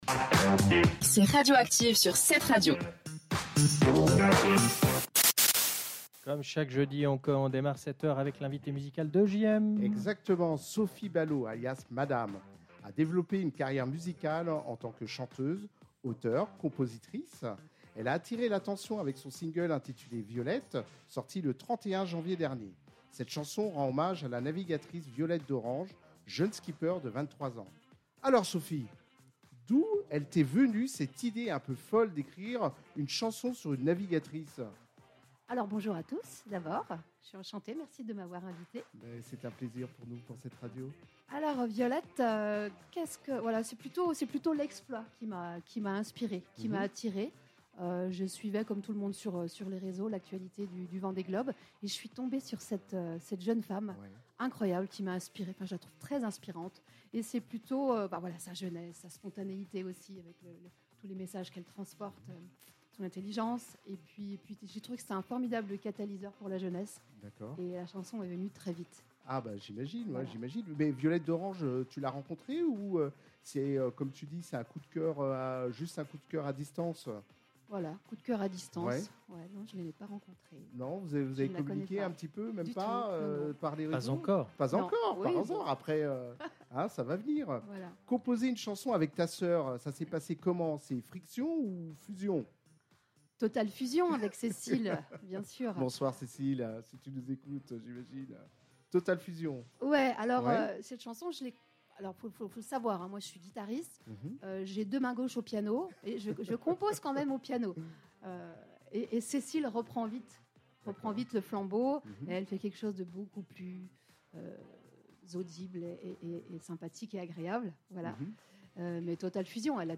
Radioactive – jeudi 15 mai 2025- Interview, partie 1